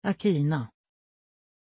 Pronunciation of Akina
pronunciation-akina-sv.mp3